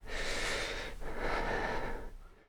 Player_UI [2].wav